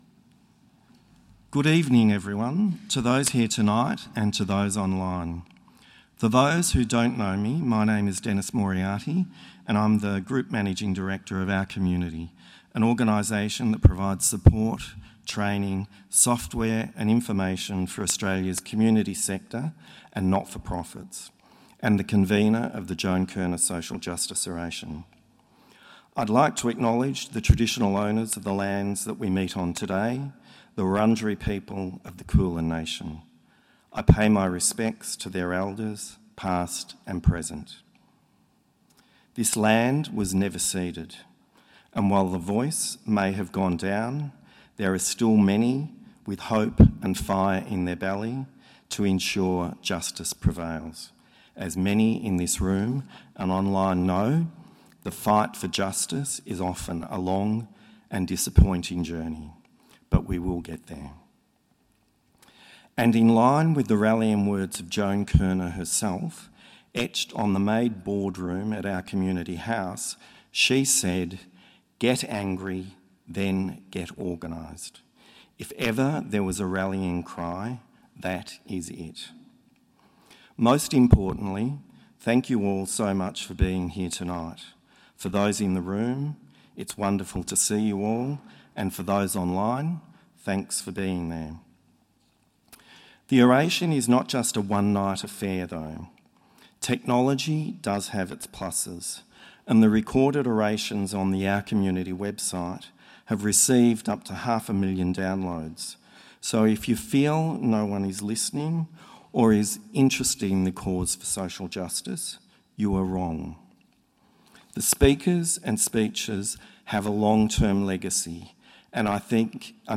The Honourable Tanya Plibersek MP, Minister for the Environment and Water delivering the keynote Joan Kirner Social Justice Oration at the 2024 Communities in Control conference in Melbourne.